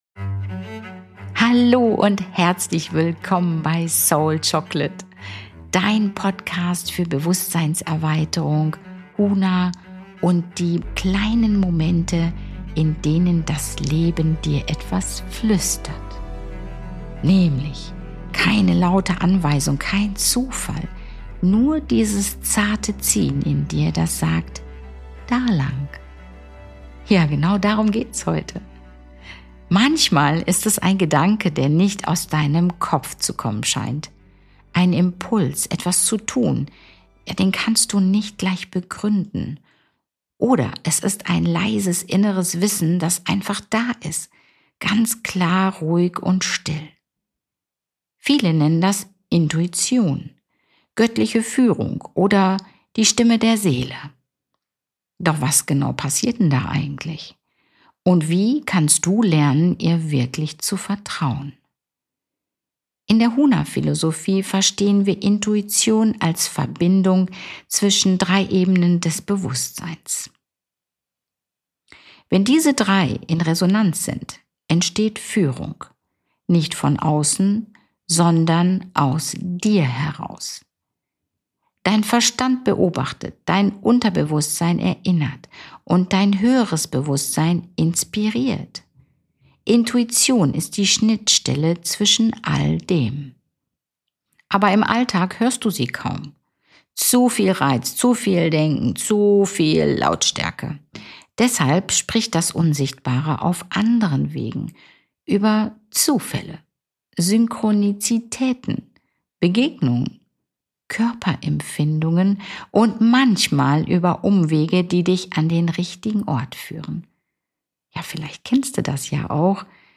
Du erfährst, wie das Unsichtbare über Synchronizitäten und Körperimpulse spricht, lernst, innere Signale zu deuten, und bekommst eine einfache Praxis für deinen Alltag. Eine ruhige, inspirierende Episode für alle, die spüren, dass mehr existiert, als man sehen kann – und bereit sind, dieser Führung zu folgen.